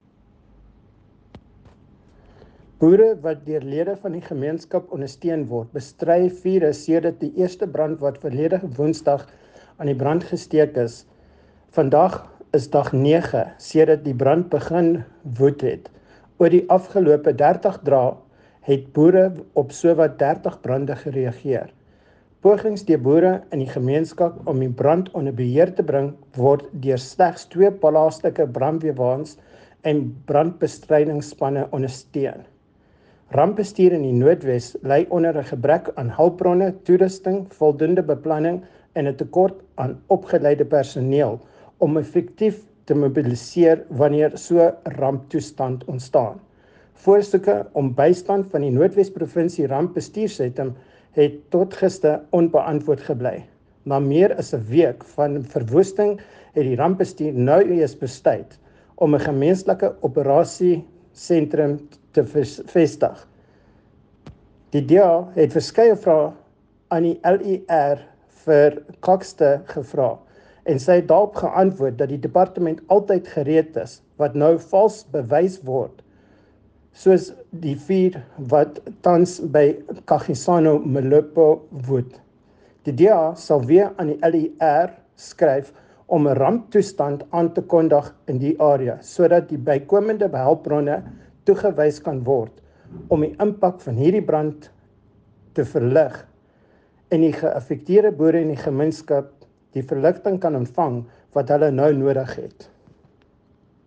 Note to Editors: Please find the attached soundbite in
Afrikaans by Gavin Edwards MPL and in